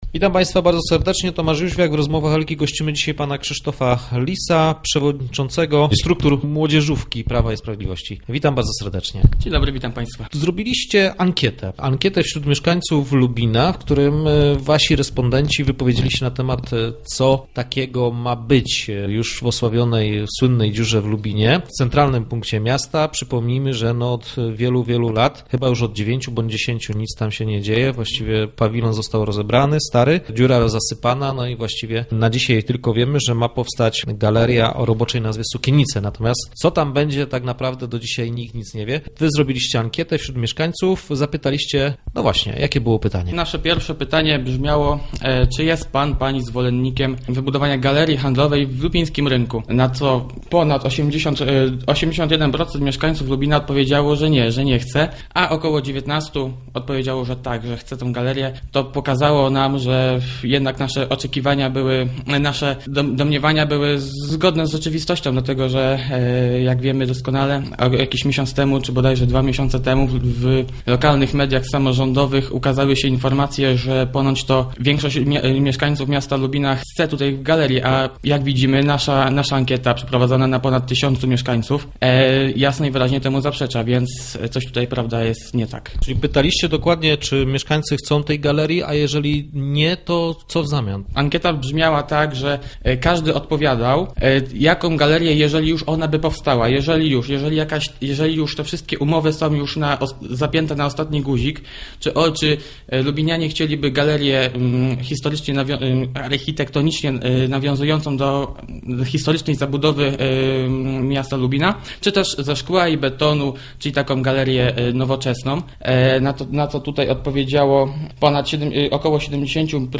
Start arrow Rozmowy Elki